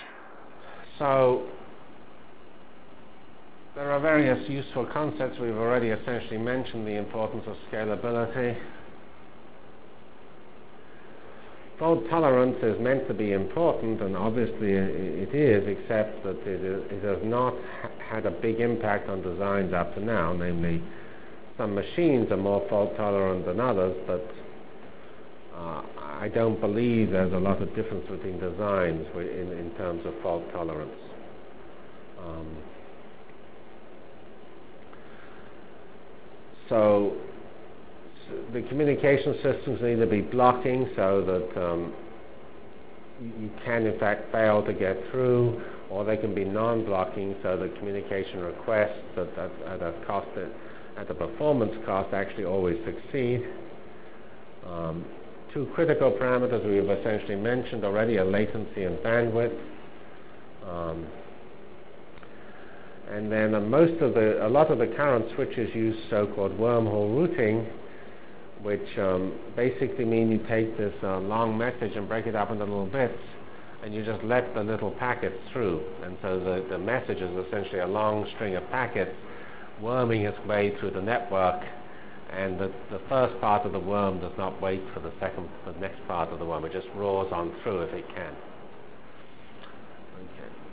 From CPS615-Lecture on Computer Architectures and Networks Delivered Lectures of CPS615 Basic Simulation Track for Computational Science -- 12 September 96. by Geoffrey C. Fox *